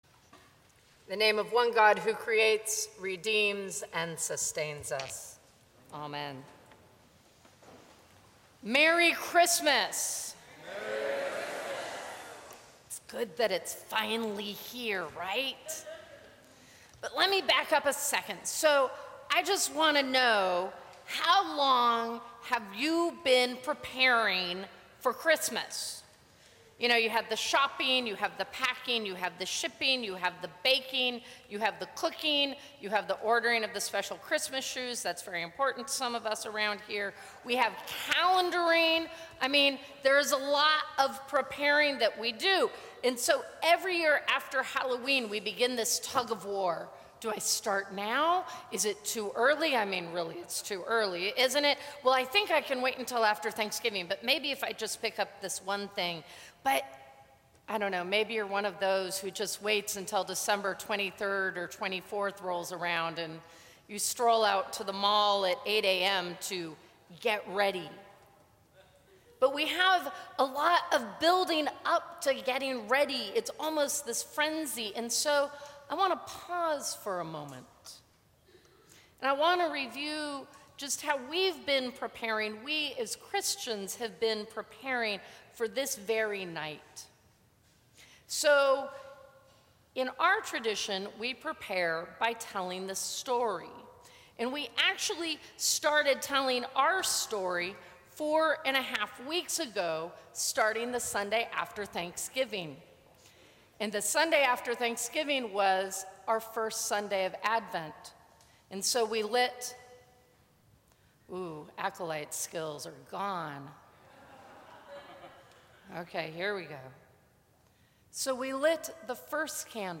Sermons from St. Cross Episcopal Church Christmas Eve Jan 04 2016 | 00:15:32 1x 00:00 / 00:15:32 Subscribe Share Apple Podcasts Spotify Overcast RSS Feed Share Link Embed